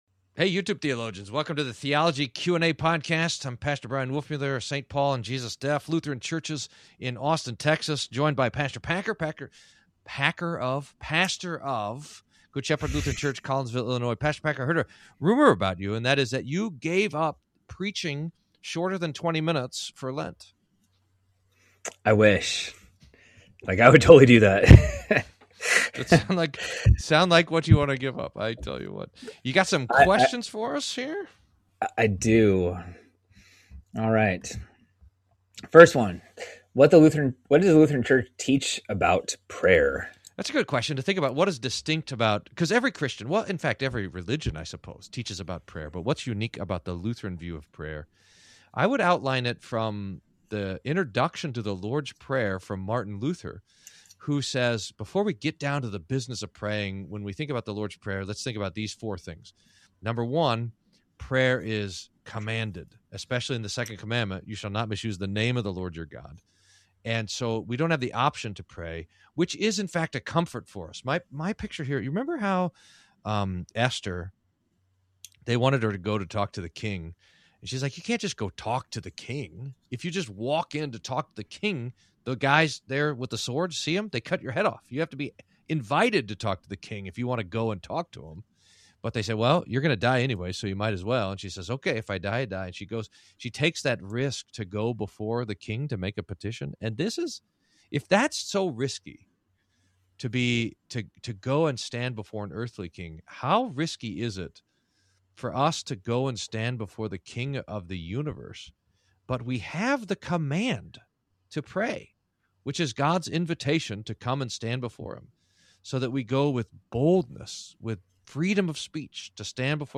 Q&A: What do Lutherans teach about prayer?